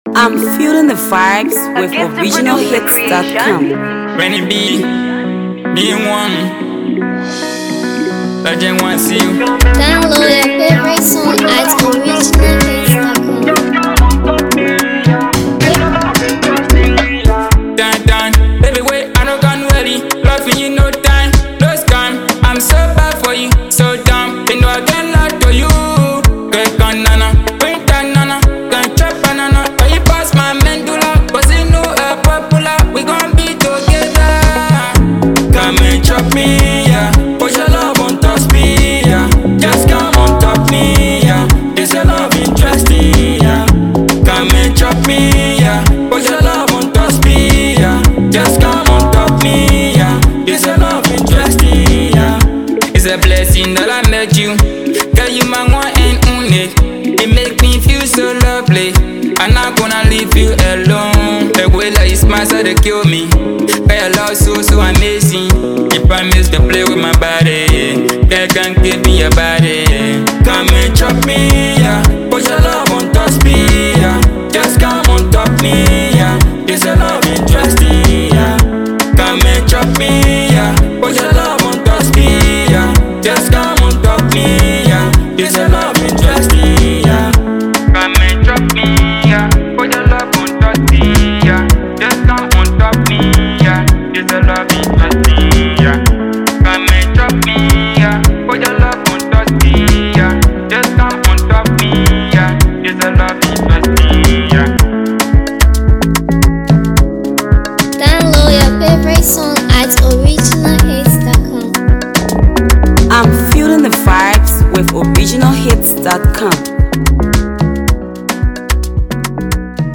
Talented Liberian artist